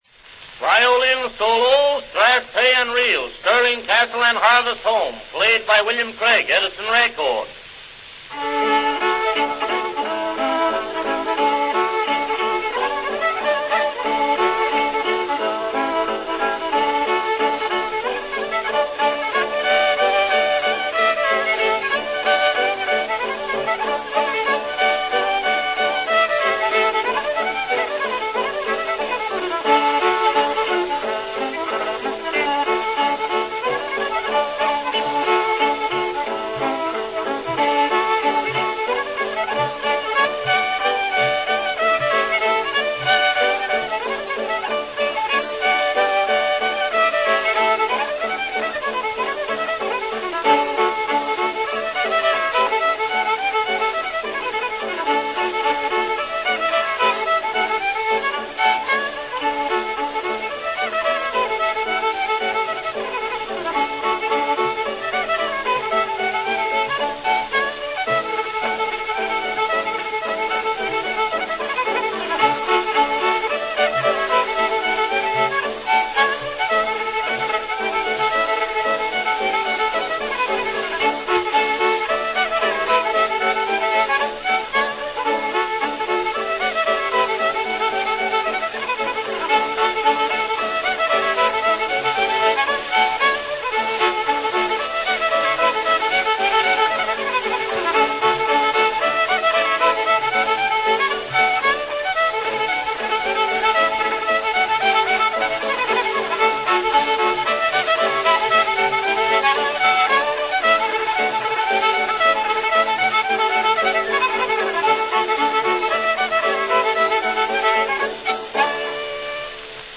Listen to a complete two-minute wax cylinder recording -- A new cylinder every month.